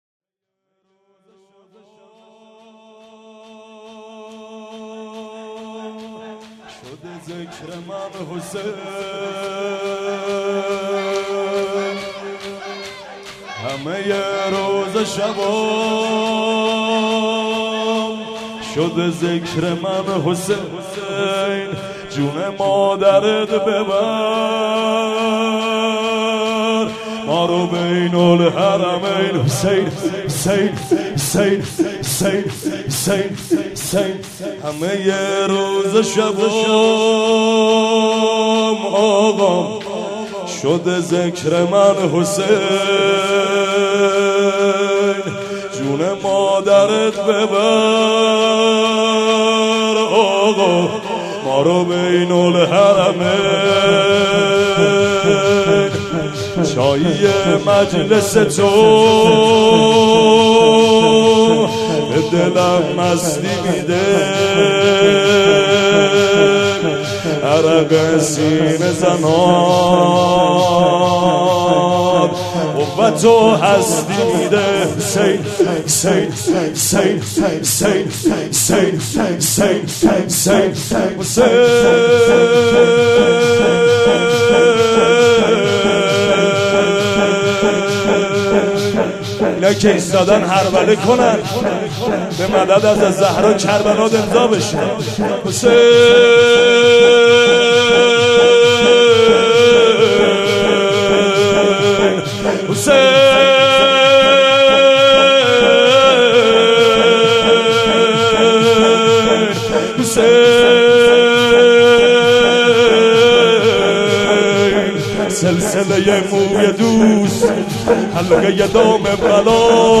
مناسبت : شب هجدهم رمضان
مداح : محمدرضا طاهری قالب : شور
05.sineh zani.mp3